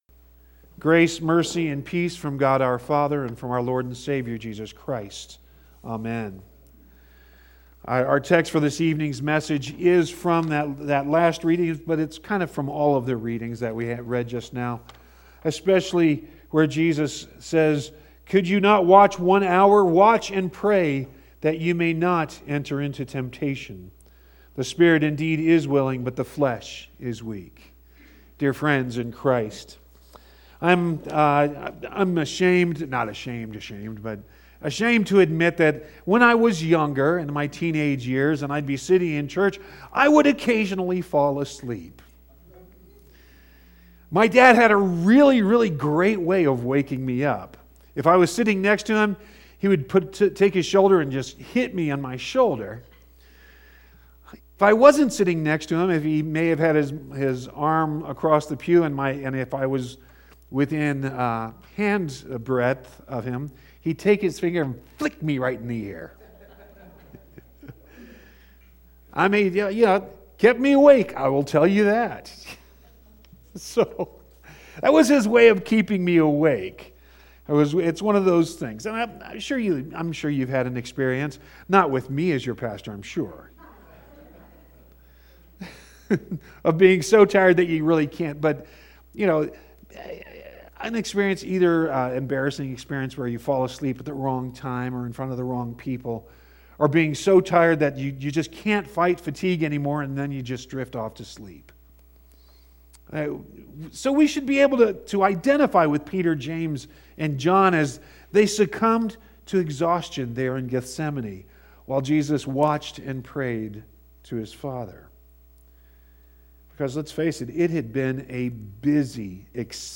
Third Wednesday of Lent 03.11.20 | Good Shepherd Lutheran Church